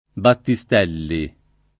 [ batti S t $ lli ]